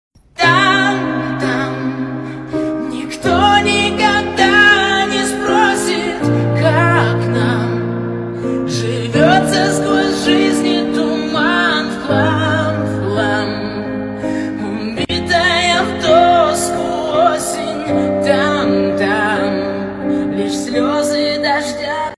Именно это выступление, где девушка поёт...